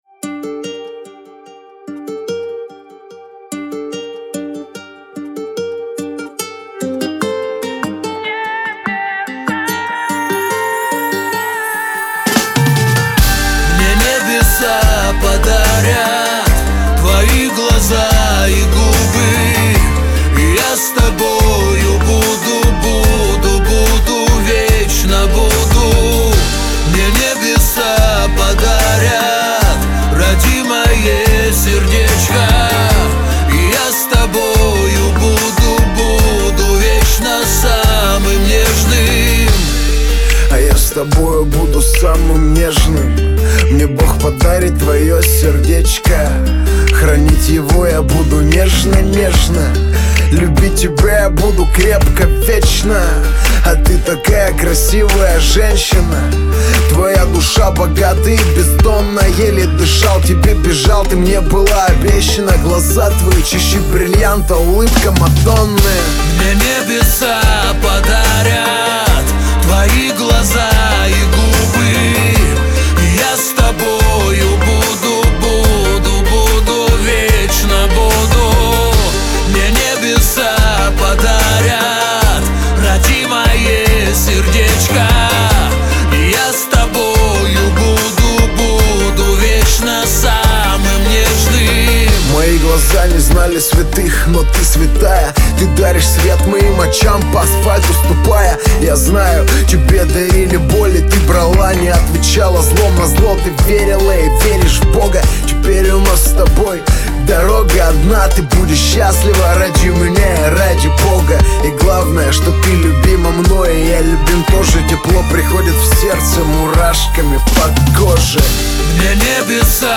Главная » Файлы » Русский рэп, хип-хоп Категория